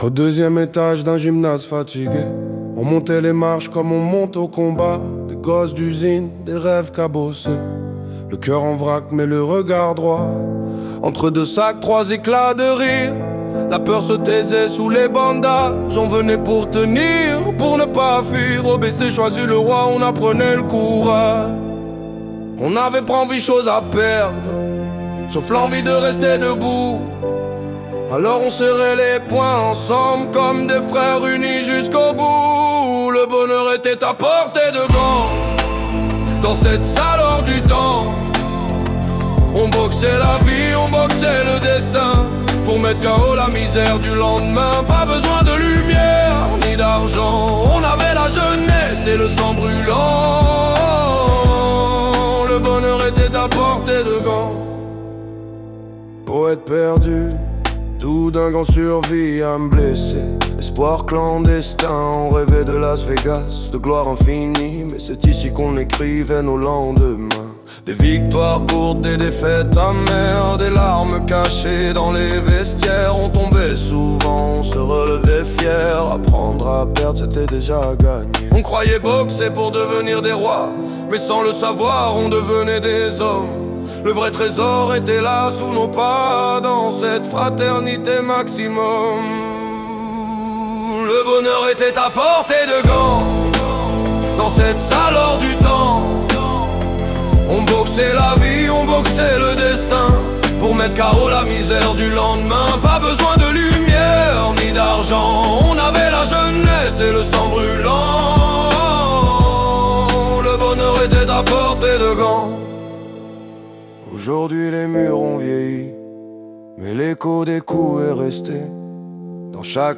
Un morceau qui fait revivre avec émotion l'histoire du BC Choisy-le-Roi